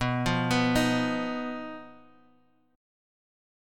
BMb5 Chord